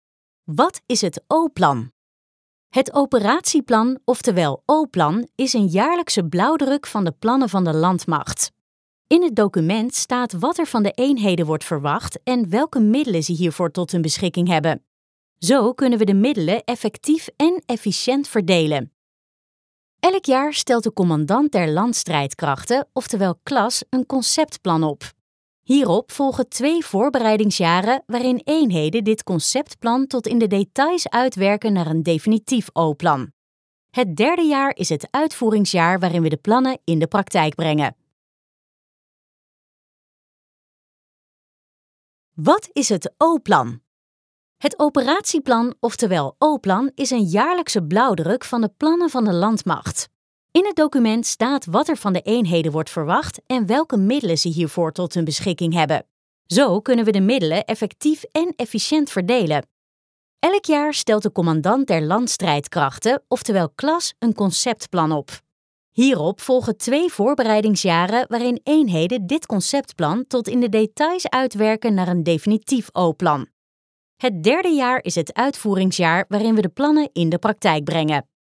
Female
TEENS, 20s, 30s, 40s
Assured, Bright, Bubbly, Character, Cheeky, Children, Confident, Corporate, Engaging, Friendly, Natural, Reassuring, Soft, Warm, Versatile, Young, Approachable, Conversational, Energetic, Funny, Sarcastic, Smooth, Upbeat, Wacky, Witty
Voice reels
ALLROUND compilation.mp3
Microphone: Rode NT1-A